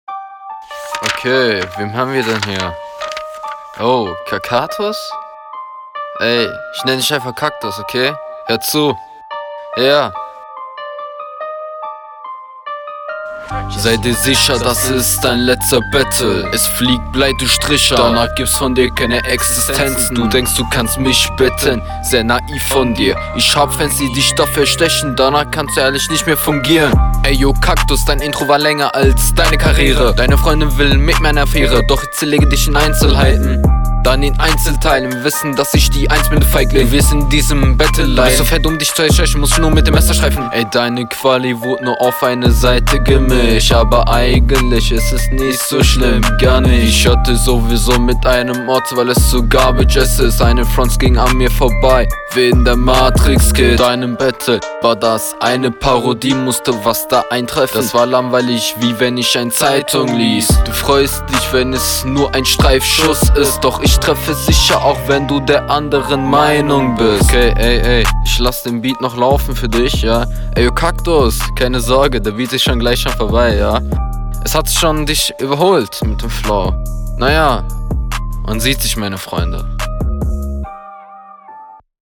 Ja flowlich hat dein Gegner dir ne schippe voraus.